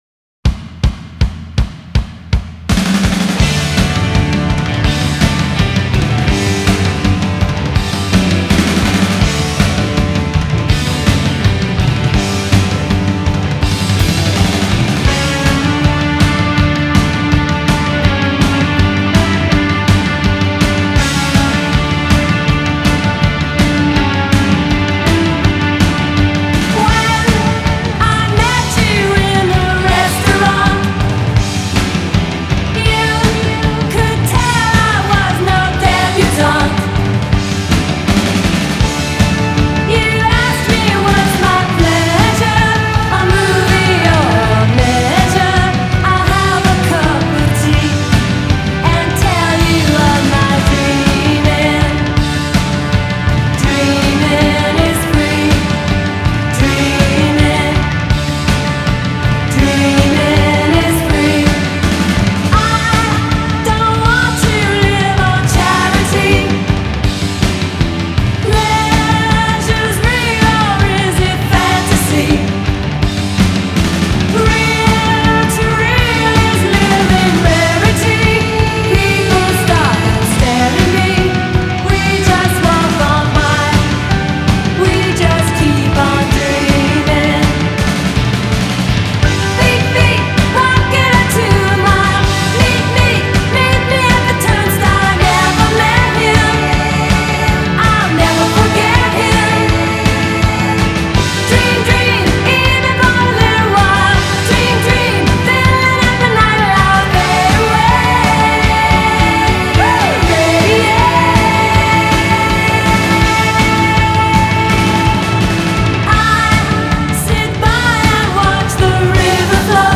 американская группа, пионеры новой волны и панк-рока.